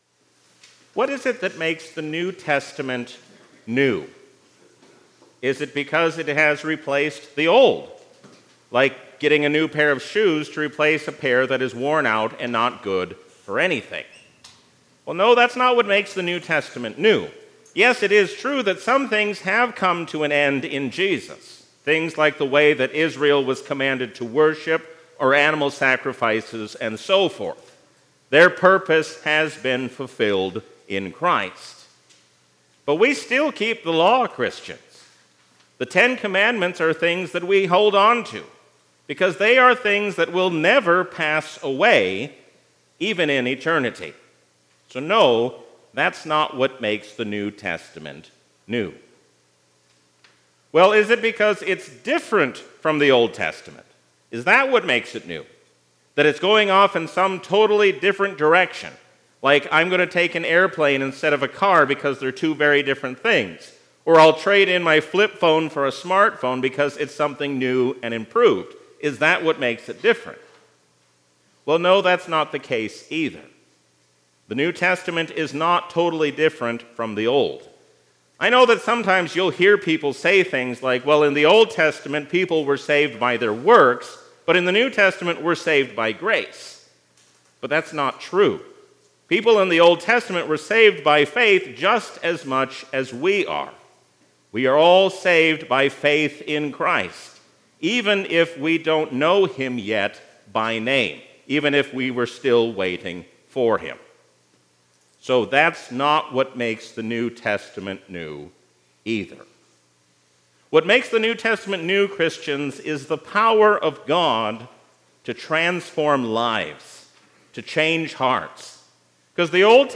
A sermon from the season "Gesimatide 2024."